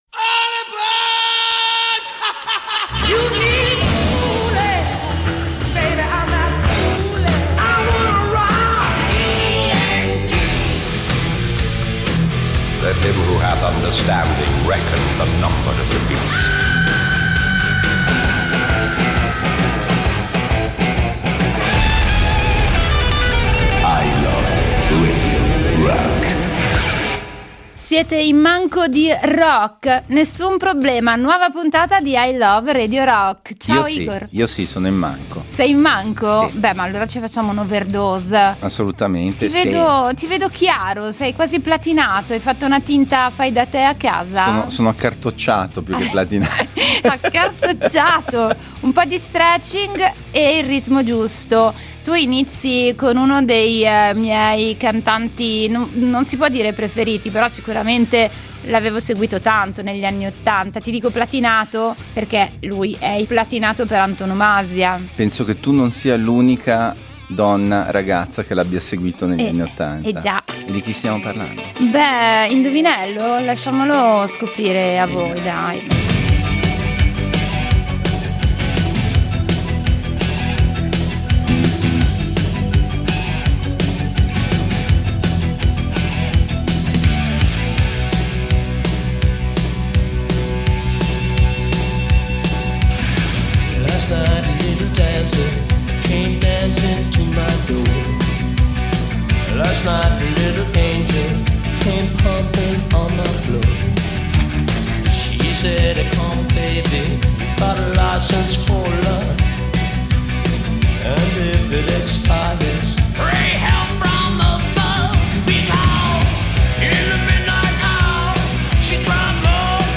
il programma dedicato alle sonorità hard rock e heavy metal che hanno fatto la storia. https